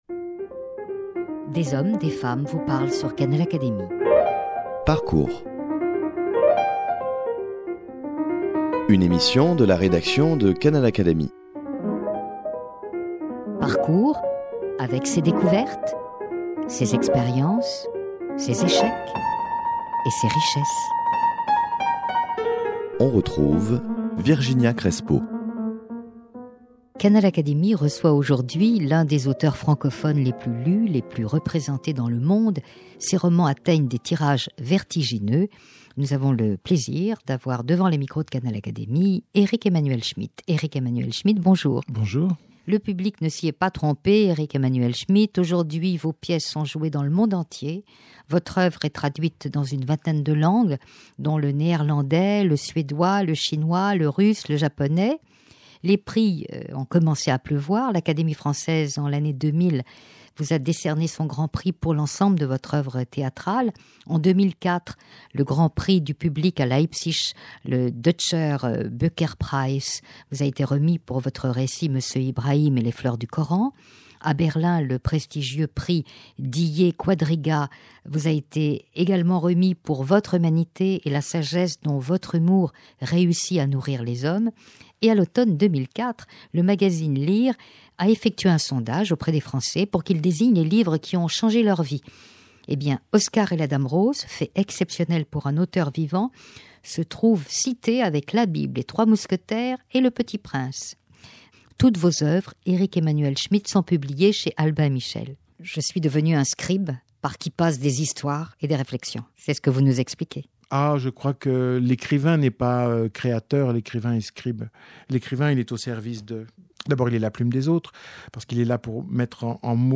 Canal Académie reçoit aujourd’hui l’un des auteurs francophones les plus lus et les plus représentés dans le monde, ses romans atteignent des tirages vertigineux (entre 200 000 exemplaires - pour L'évangile selon Pilate - et 400 000 exemplaires vendus - pour Oscar et La dame rose on l'étudie dans les lycées, il se publie de fort érudites exégèses de son œuvre et une étude américaine de Publishing Trends le place sur la liste des quinze écrivains les plus lus dans le monde - il est le seul auteur français cité.